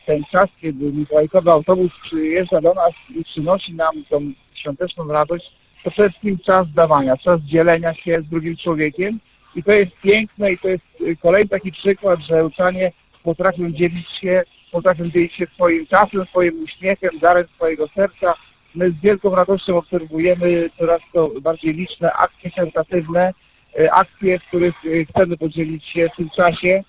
Byliśmy też w Ełckim ratuszu, tu nas przyjął zastępca prezydenta miasta Artur Urbański
zastępca-prezydenta-miasta-Artur-Urbański.mp3